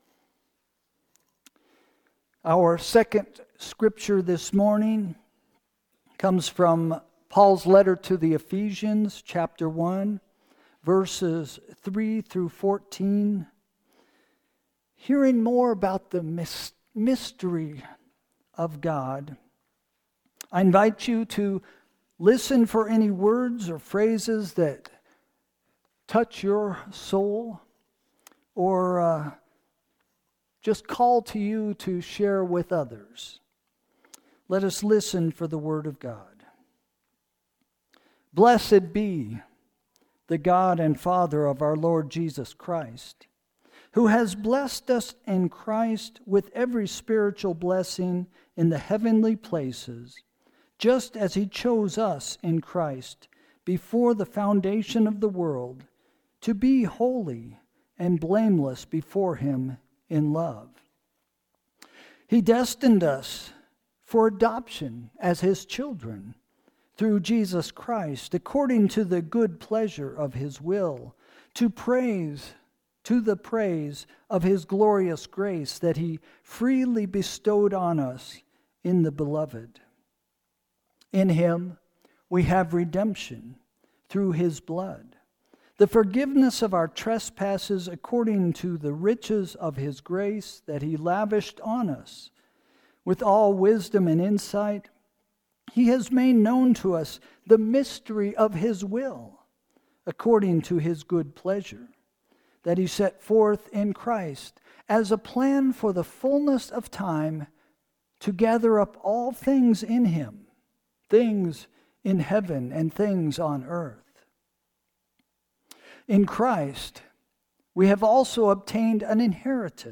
Sermon – September 14, 2025 – “The Mystery of Life” – First Christian Church